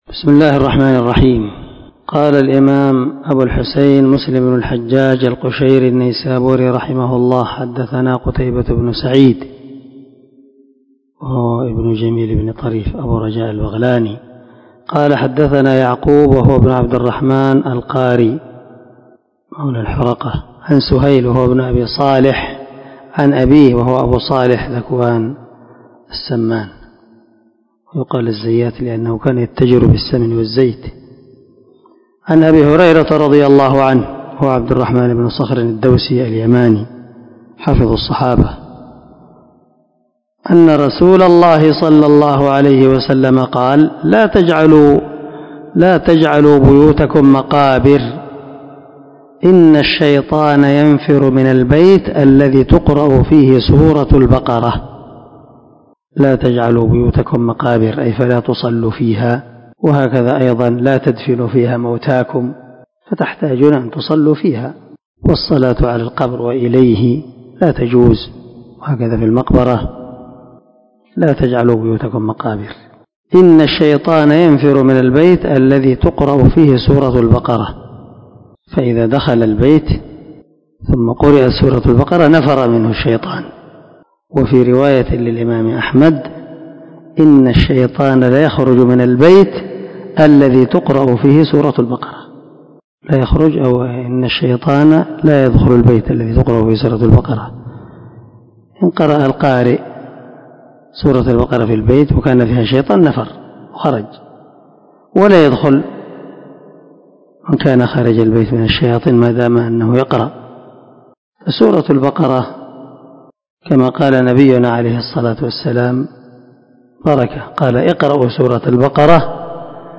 473الدرس 41 من شرح كتاب صلاة المسافر وقصرها حديث رقم ( 780 – 781 ) من صحيح مسلم